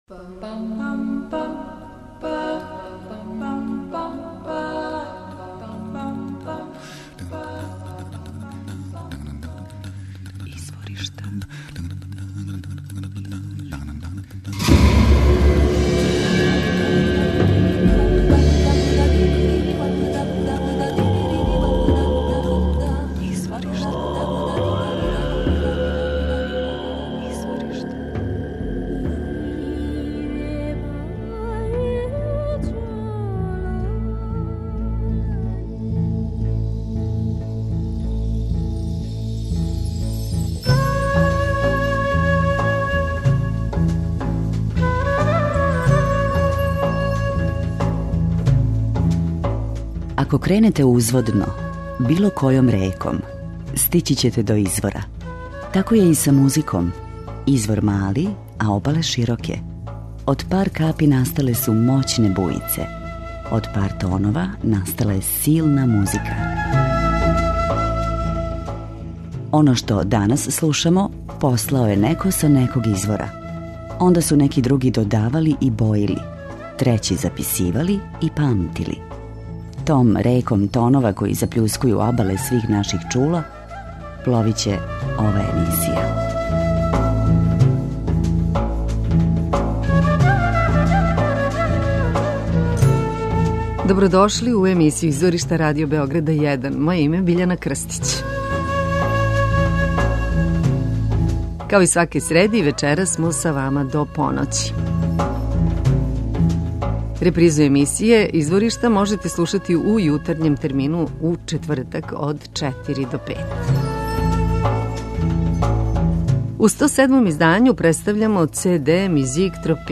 -Музичари из афричких земаља и сенегалски певачи,чине групу Africando.